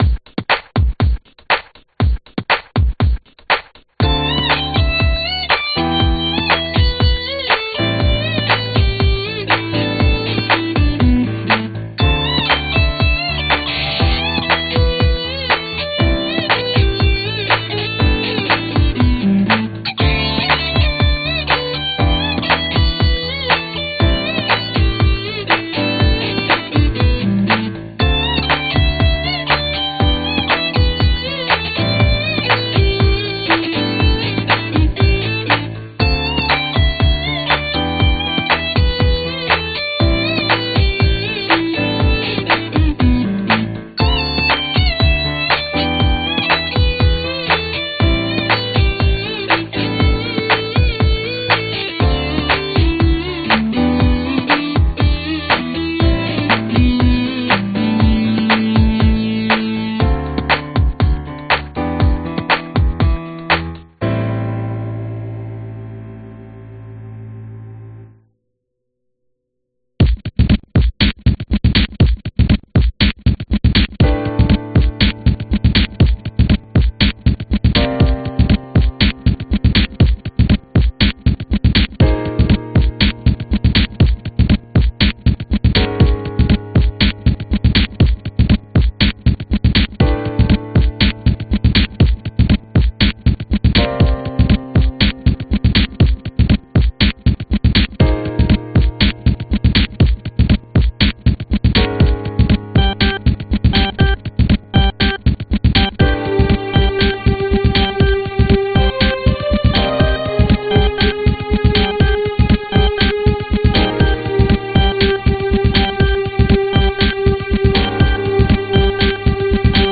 Tag: 嘻哈 爵士 钢琴 合成器 弦乐 管弦乐 欢快 器乐